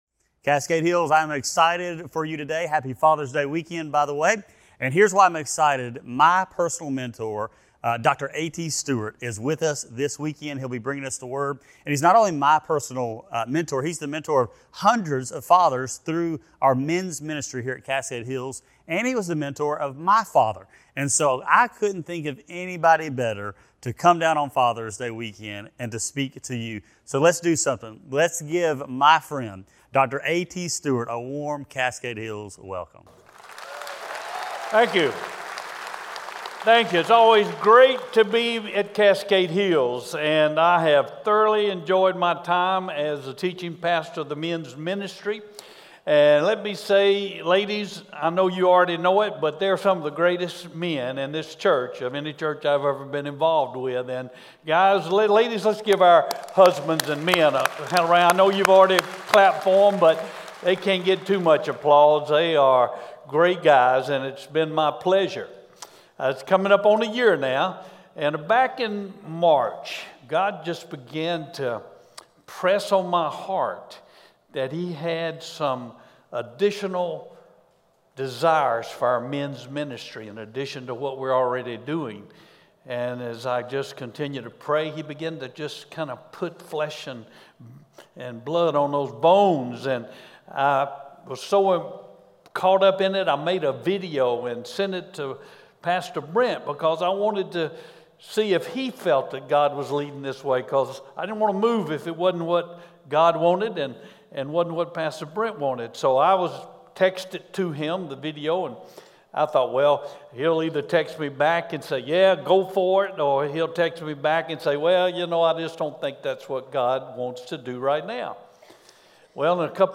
a special Father's Day message